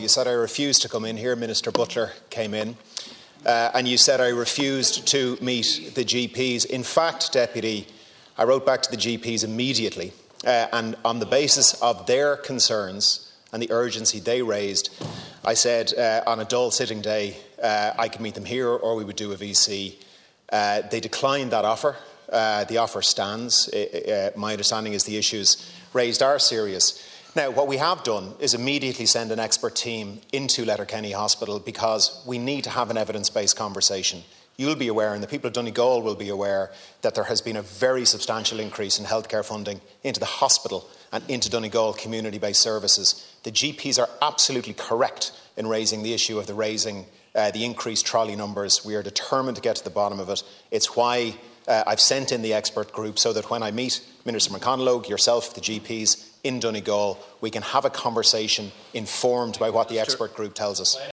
Minister Donnelly, today in responding to Deputy Doherty did not rule out a future visit to Donegal but stopped short of giving exact date: